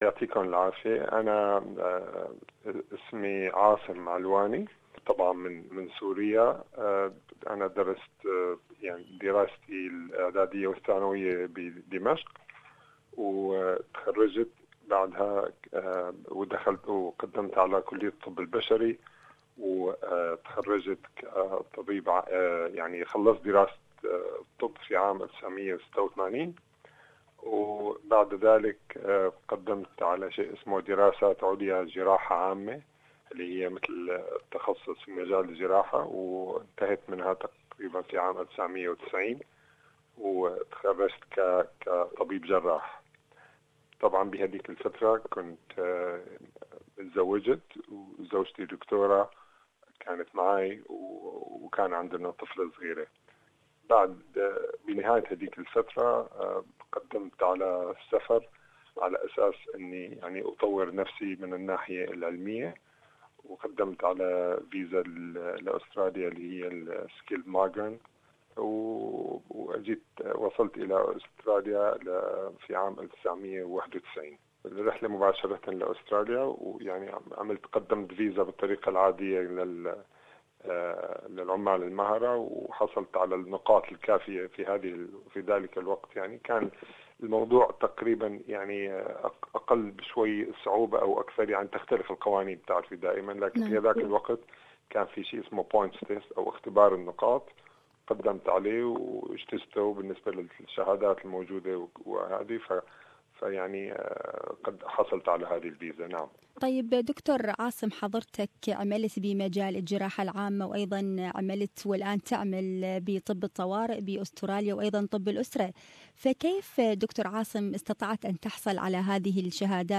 الحوار